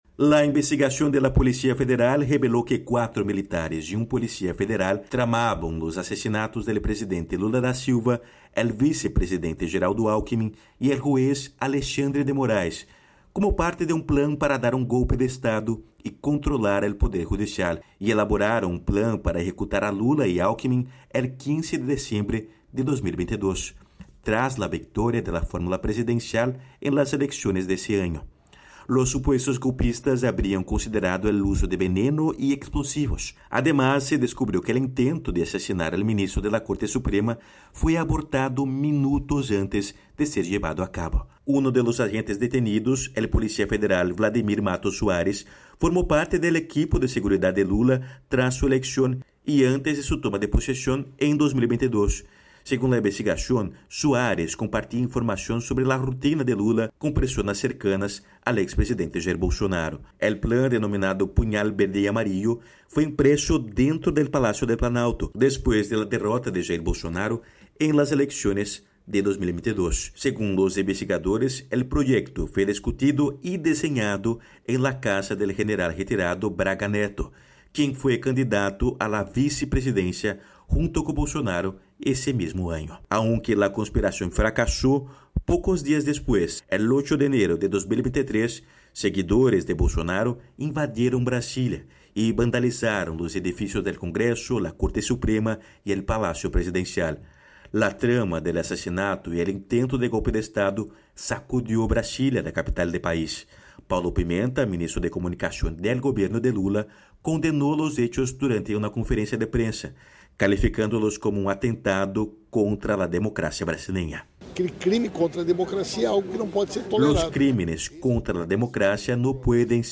En Brasil, emergen nuevos detalles sobre el plan para asesinar al presidente Lula da Silva y a otros altos funcionarios y se detienen a sospechosos acusados de planificar los asesinatos y un golpe de Estado en 2022. Desde Brasil informa el corresponsal de la Voz de América